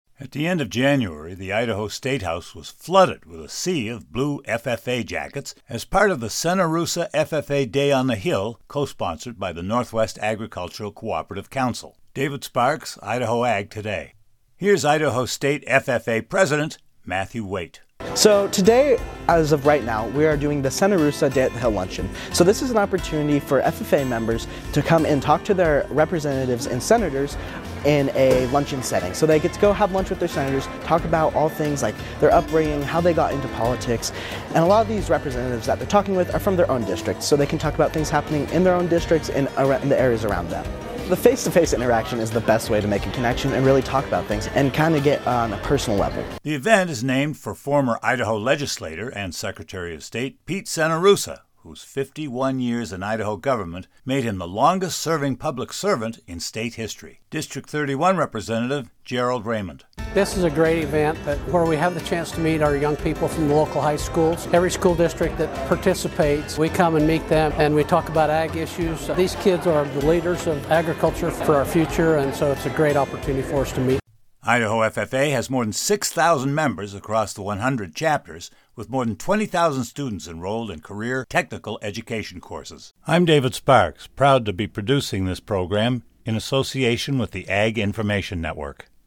It included a legislative luncheon at the Riverside Hotel, and opportunities for FFA members to meet with lawmakers, tour the Capitol, and strengthen civic leadership skills.